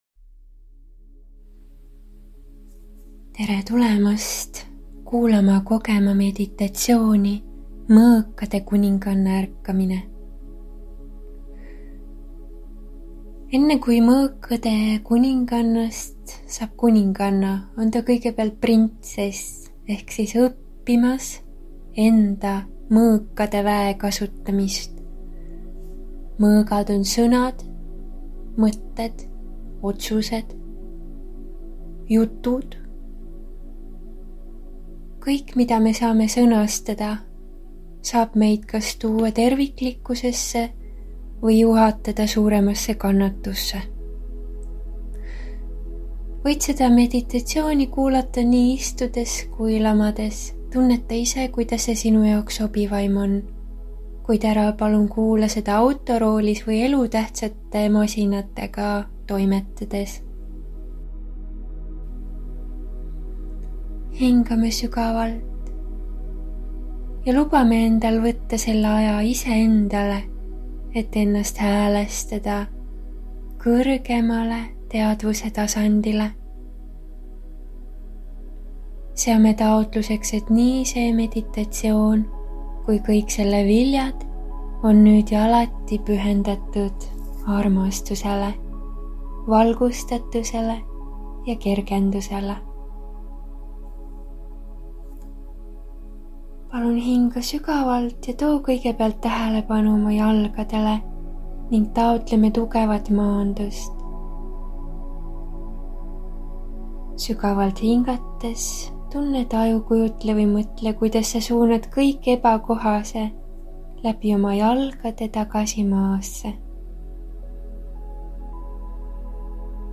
meditatsioon